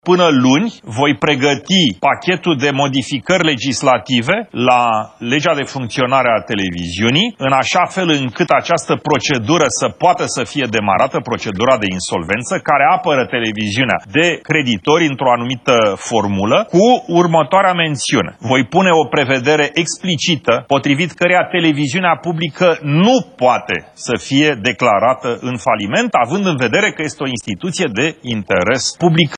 Declaraţia a fost făcută într-o dezbatere la TVR, de preşedintele Senatului, Călin Popescu Tăriceanu.